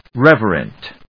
rev・er・ent /rév(ə)rənt/
• / rév(ə)rənt(米国英語)